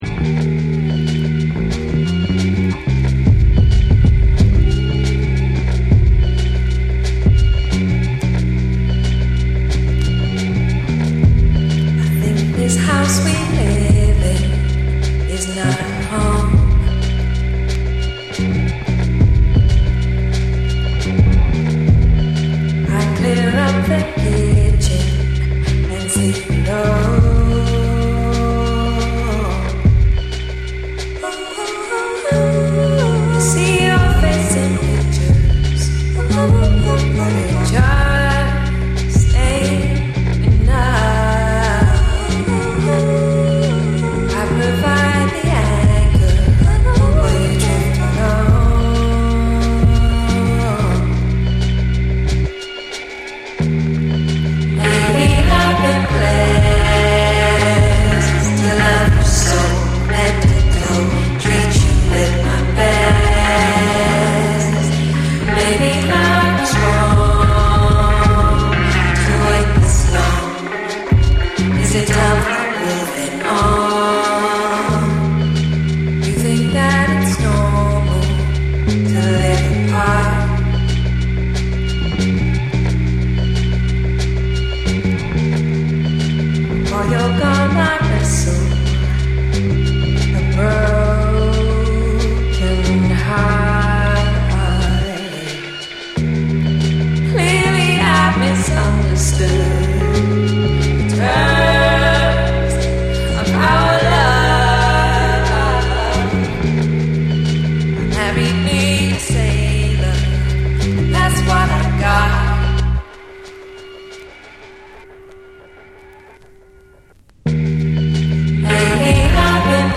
BREAKBEATS